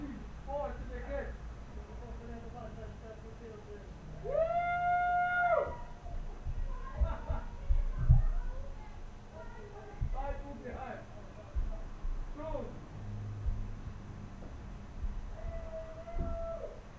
Um den Jahreswechsel 2015/2016 war ich auch im Urlaub, hier ist das Musikerlebnis untermalt mit viel Trittschall: